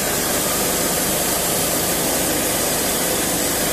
Hose_Audio_A.wav